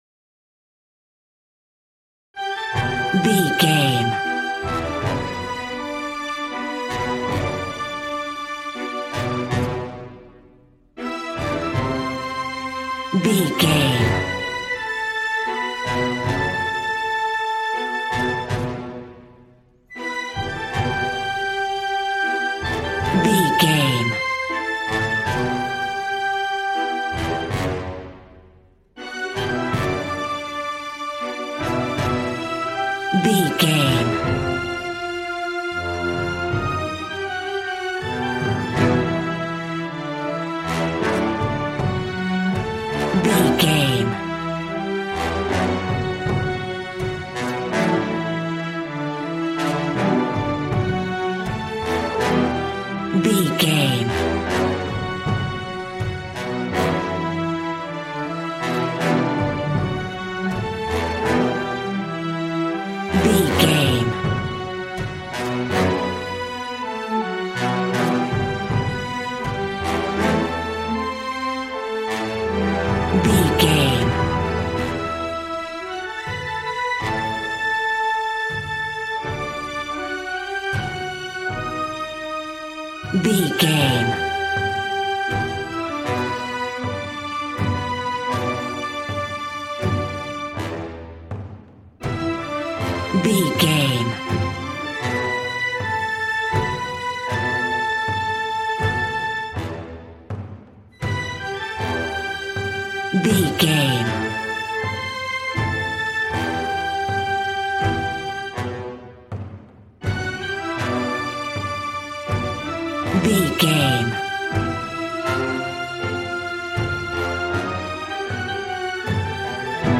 Valiant and Triumphant music for Knights and Vikings.
Regal and romantic, a classy piece of classical music.
Aeolian/Minor
brass
strings
violin
regal